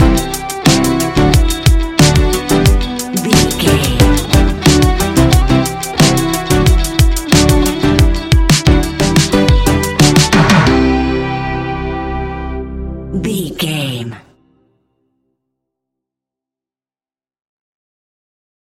Ionian/Major
ambient
electronic
new age
chill out
downtempo
synth
pads
space music
drone